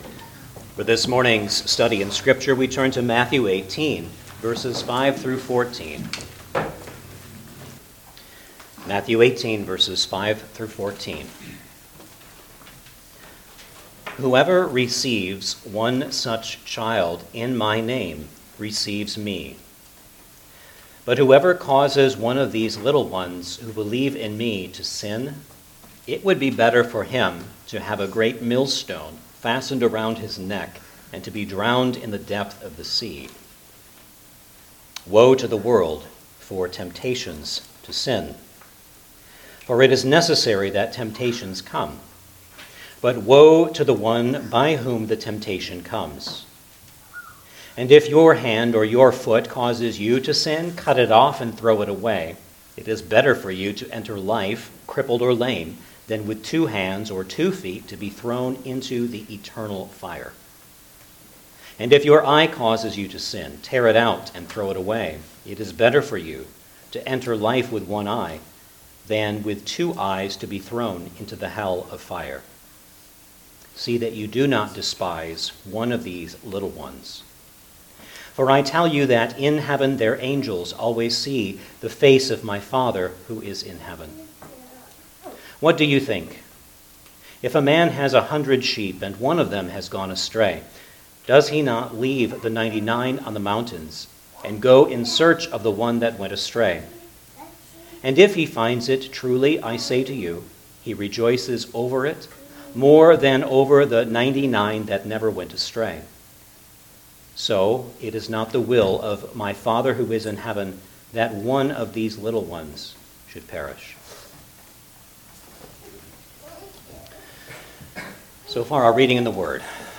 Gospel of Matthew Passage: Matthew 18:5-14 Service Type: Sunday Morning Service Download the order of worship here .